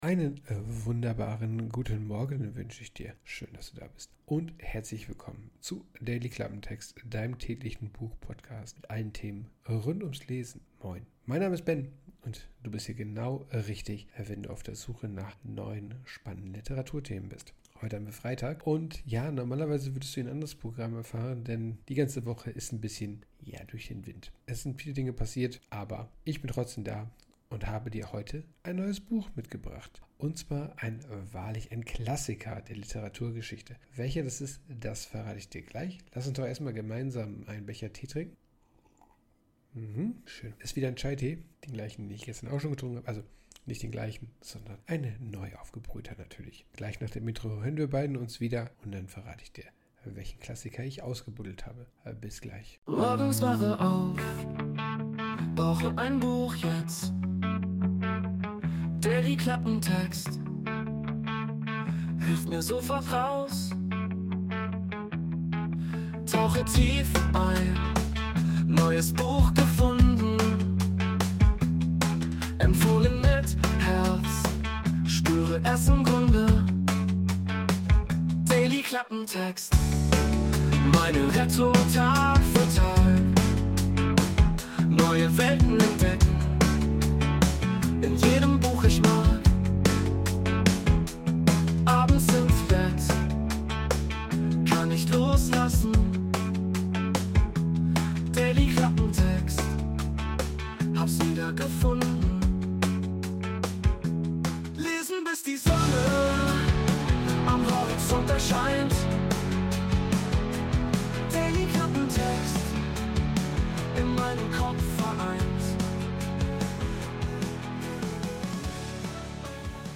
Orchestral Sweeping Dramatic Music
Intromusik: Wurde mit der KI Suno erstellt.